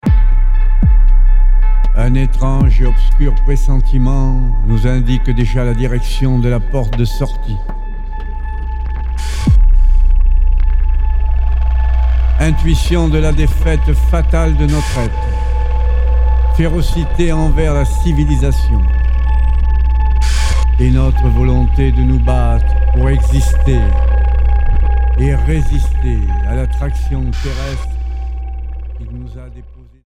Texte et voix